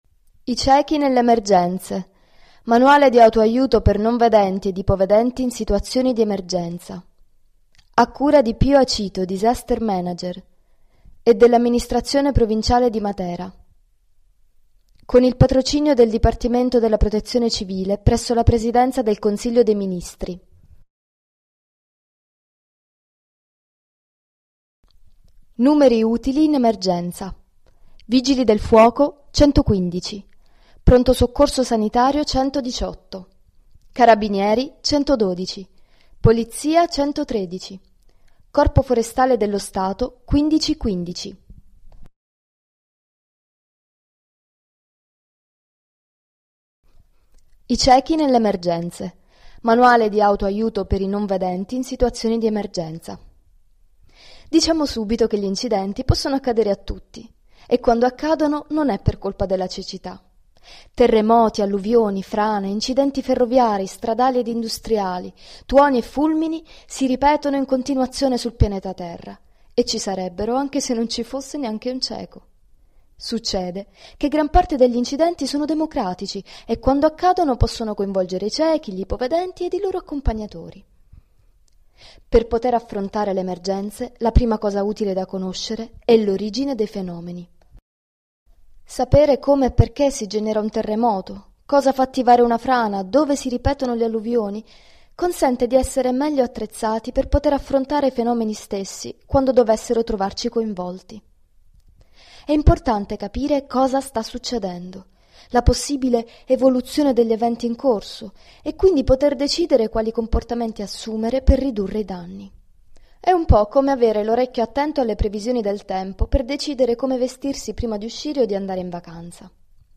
Scarica o ascolta l’Audiolibro - I ciechi nelle emergenze .mp3, 13,4 MB
audiolibro-ciechi-emergenza.mp3